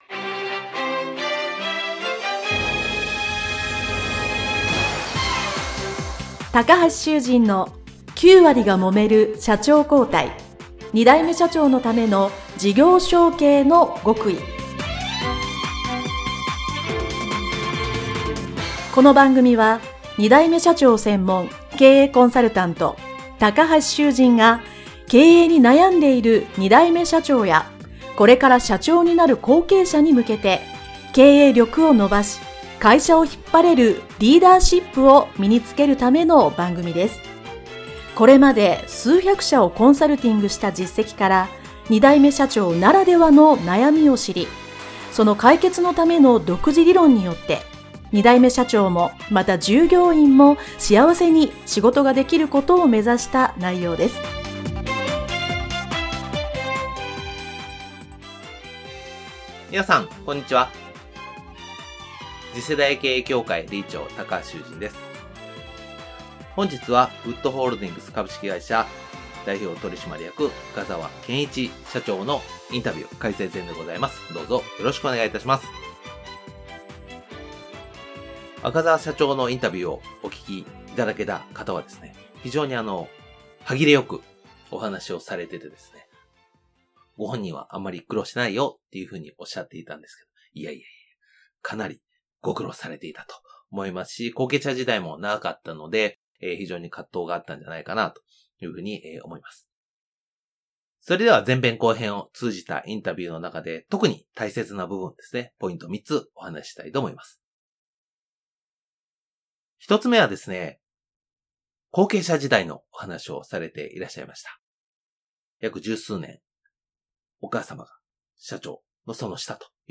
【インタビュー解説編】